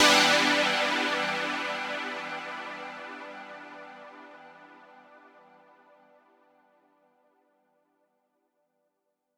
Chords_D_01.wav